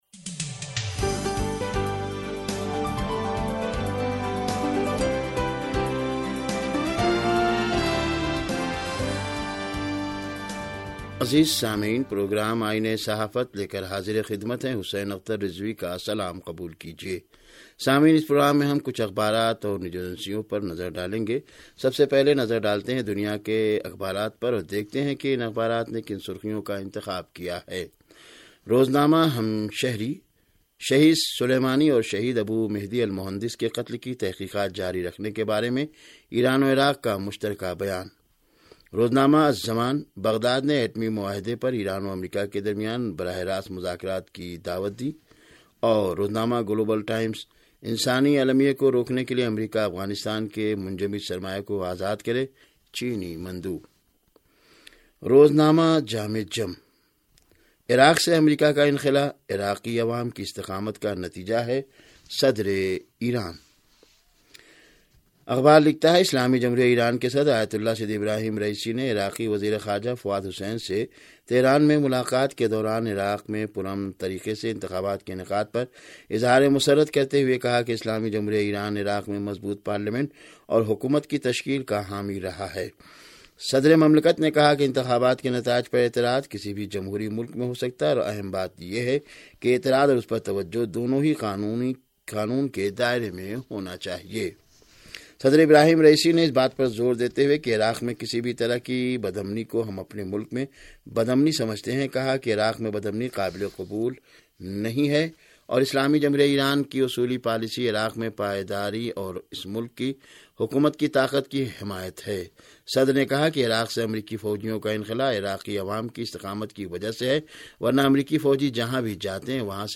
ریڈیو تہران کا اخبارات کے جائزے پرمبنی پروگرام - آئینہ صحافت